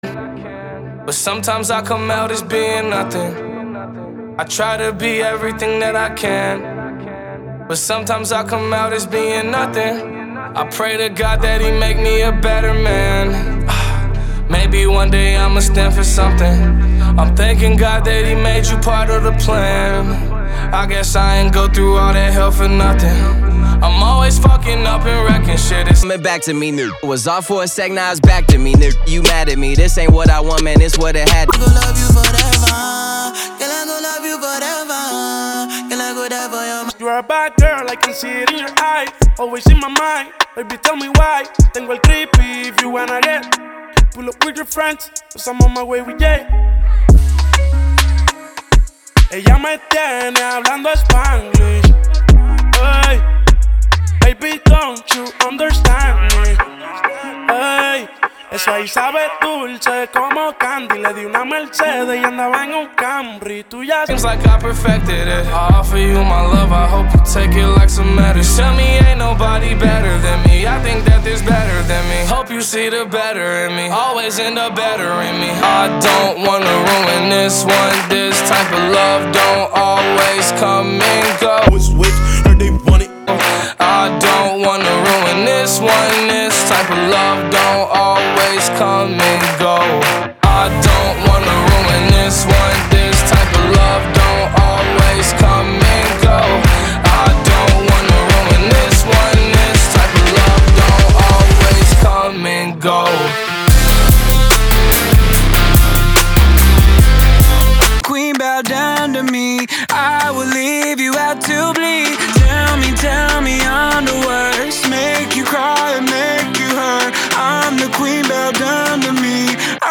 Old School RnB